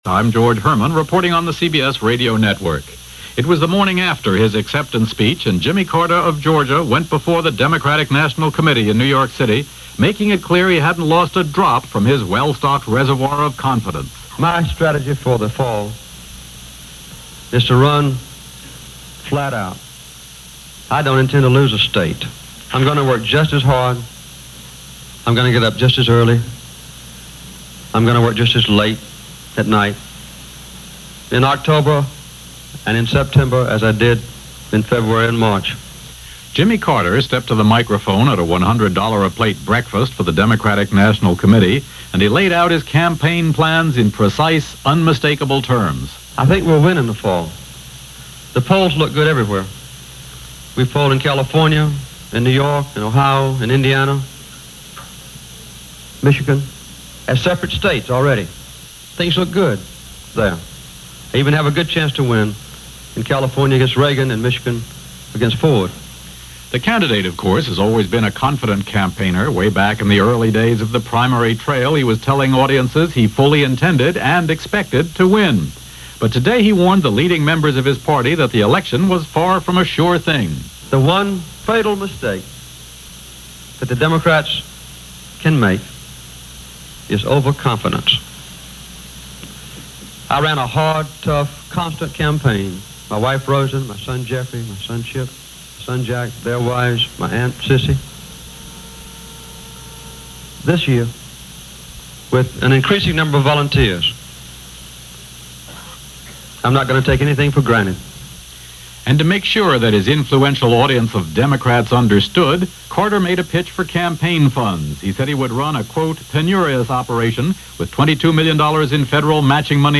Campaign '76 - Dispatches From The Trail - July/August 1976 - Past Daily After Hours Reference Room - CBS Radio Reports
Tonight and for the next few nights, I’ll be running a series of broadcasts from CBS Radio under the title Campaign ’76 – five minute reports from the campaign trail of both major political parties, primarily focusing on Jimmy Carter for the Democrats and President Ford and Ronald Reagan (who was looking to unseat the incumbent Ford in 1976) for the Republicans – no sides taken, just the nuts and bolts of getting the message out, gauging the climate, talking to voters – getting a feel for the message and the process.